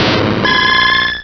Cri d'Octillery dans Pokémon Rubis et Saphir.